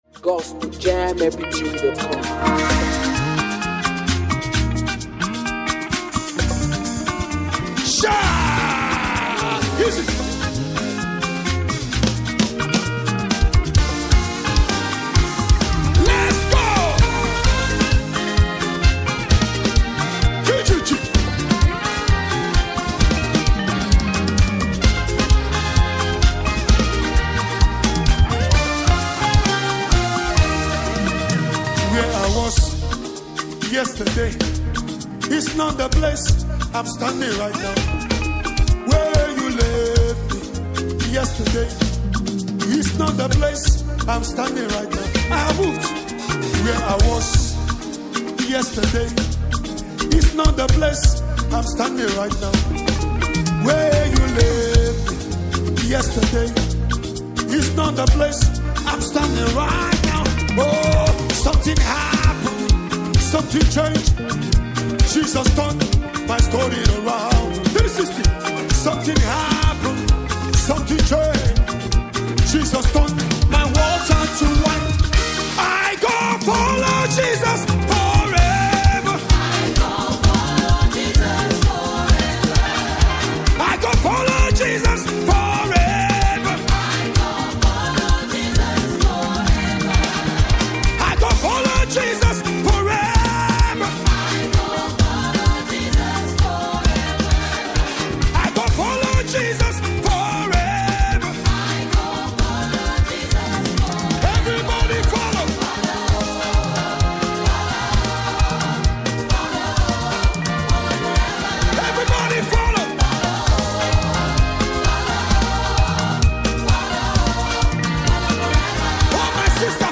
heartfelt gospel song
With a rich blend of worship and contemporary gospel rhythms
Backed by soulful vocals and uplifting instrumentals